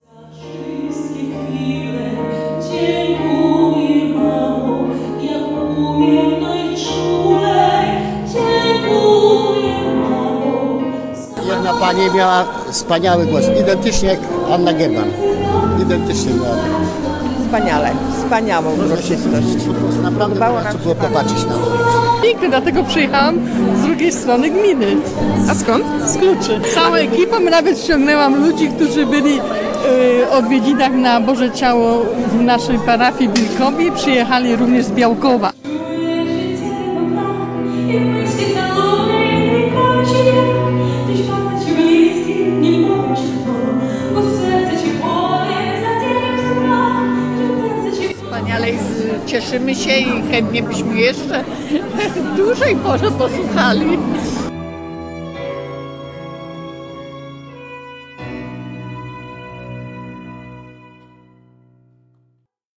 0526_koncert_przedmoscie.mp3